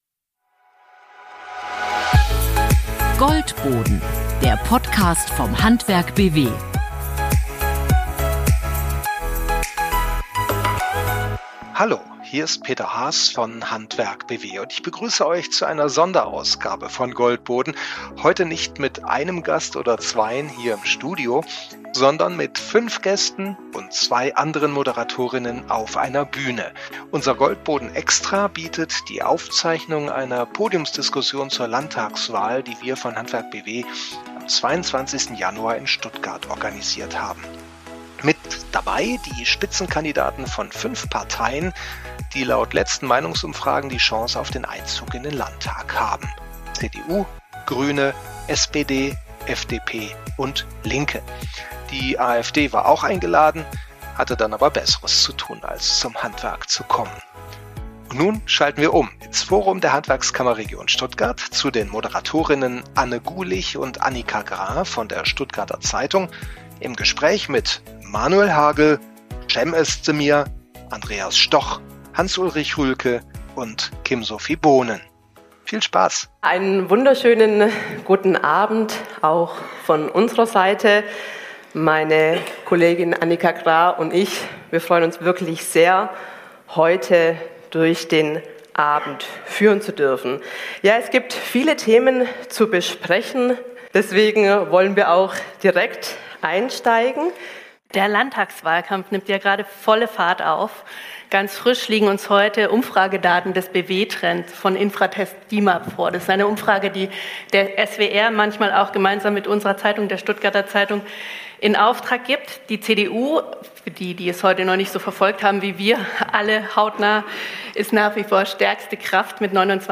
Beim „Wahlpodium Handwerk BW 2026“ treffen die fünf Spitzenkandidaten von CDU, Grünen, SPD, FDP und Linke auf Unternehmerinnen, Meister und Auszubildende – und auf sehr konkrete Fragen: Wie viele Stunden Bürokratie sind zumutbar?
Und wie schafft Politik endlich Planungssicherheit bei Energie- und Klimathemen? Sechs Wochen vor der Landtagswahl geht es im Forum der Handwerkskammer Region Stuttgart um mehr als Parteipositionen.
Zwischen Effizienzgesetz, Meisterprämie, Technologieoffenheit und Investitionsforderungen zeigt sich: Der Ton bleibt sachlich – aber die Unterschiede sind deutlich.